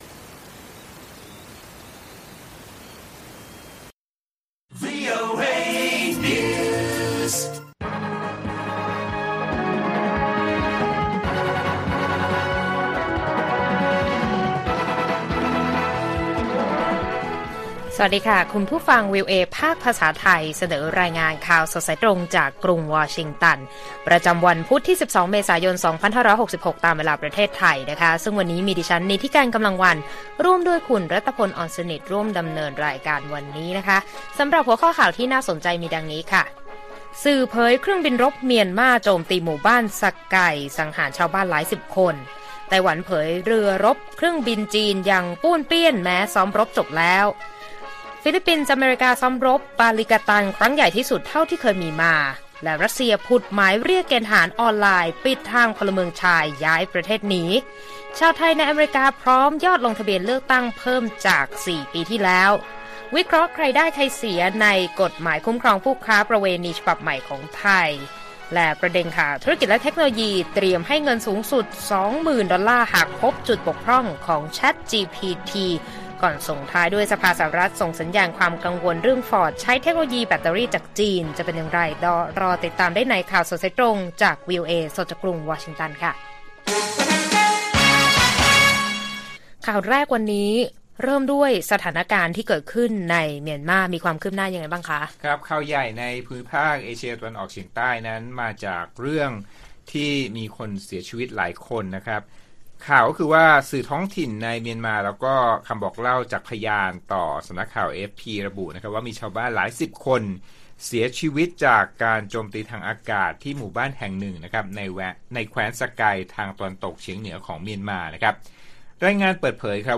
ข่าวสดสายตรงจากวีโอเอ ไทย พุธ ที่ 12 เมษายน 2566